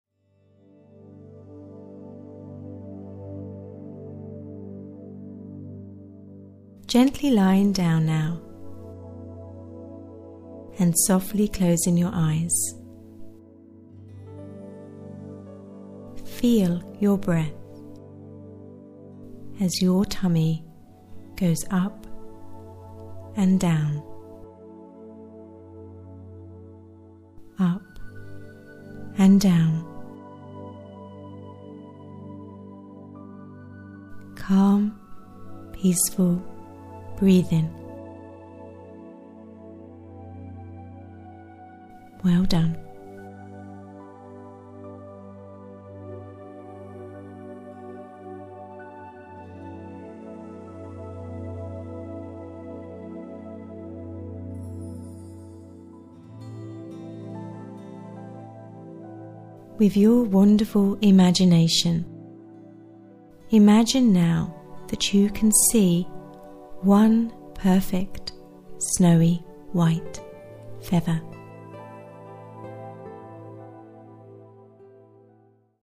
Bedtime Guided Meditation for Children